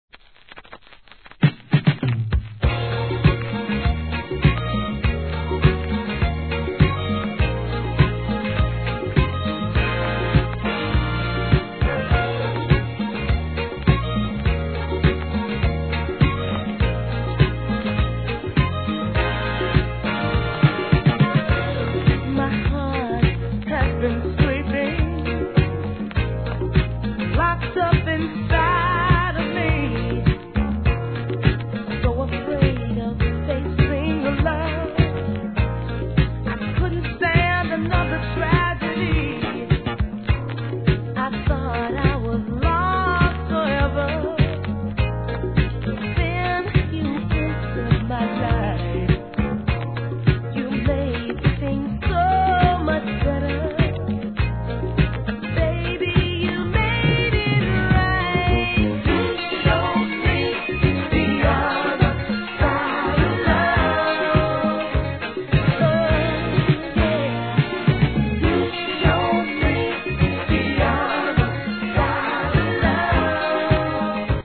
¥ 935 税込 関連カテゴリ SOUL/FUNK/etc...